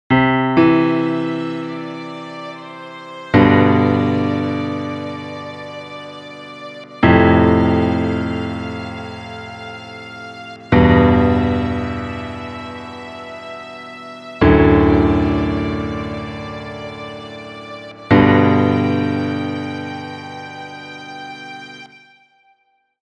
He had to create a piece of music that started off somber, but built to something more hopeful and bright.
Below is a sample of one of the themes he created which starts out somber but grows into something brighter.
I think the music fits very nicely with the atmosphere of growing abundance.